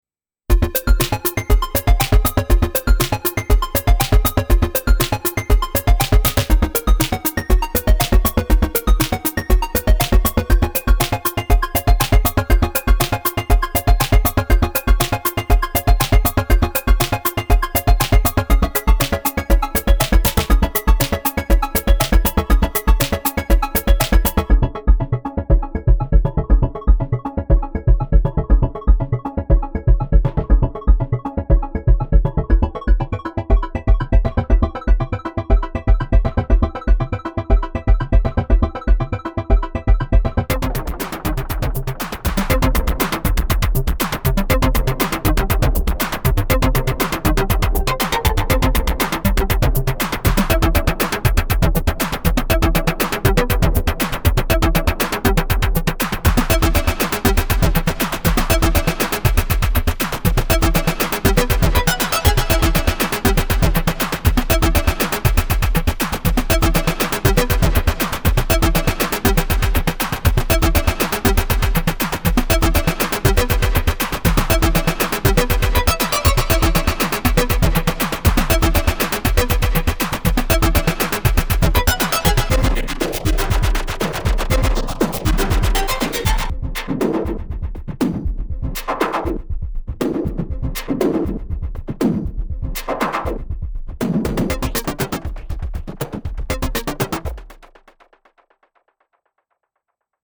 Some oddball clockwork techno attempt. Very short delay creating a tonal effect.
I like the ‘ice on ice’ sound in the second one. :smiley: